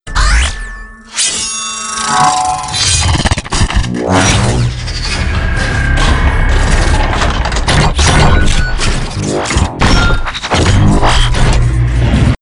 Alienware Logon.wav